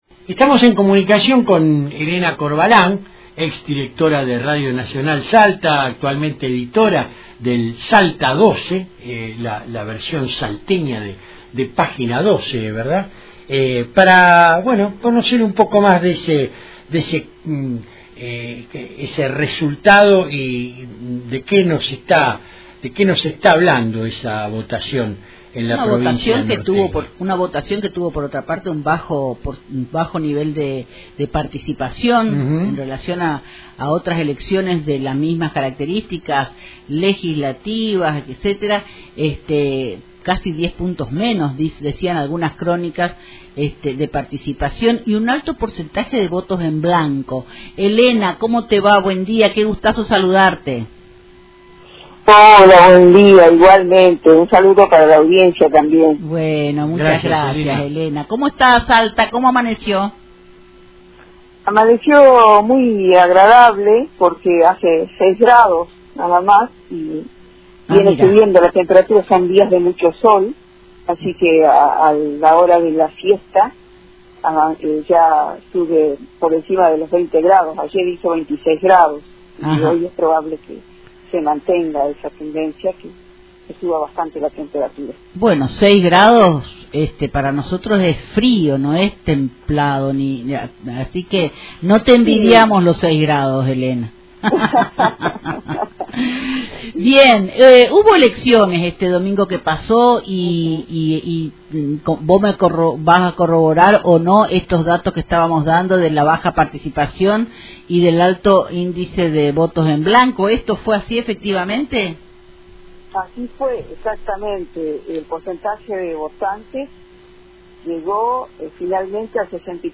en una entrevista que repasó la actualidad político-electoral salteña.